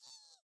voiceUki2Echo2.ogg